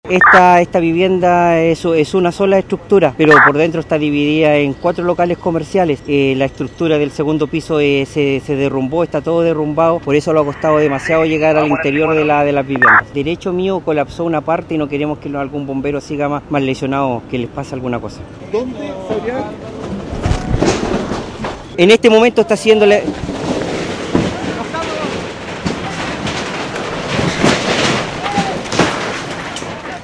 fue sorprendido con el desplome del segundo piso.